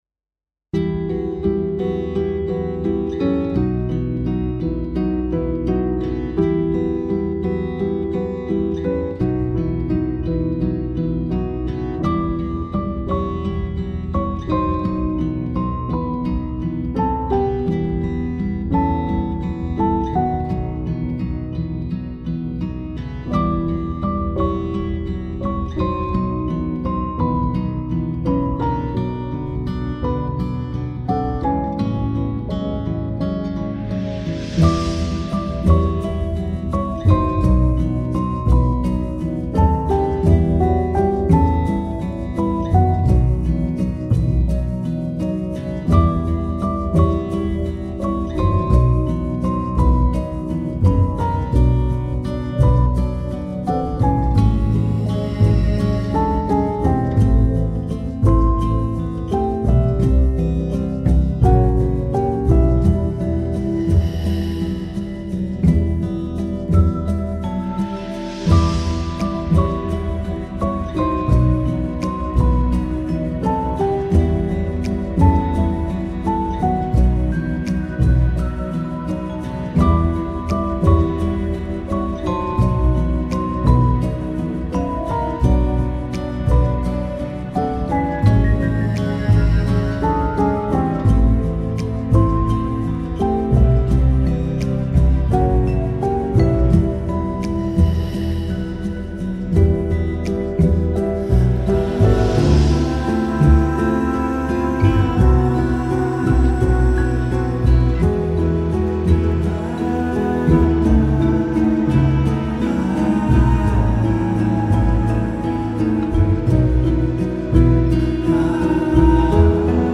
aerien - nostalgique - calme - melancolie - piano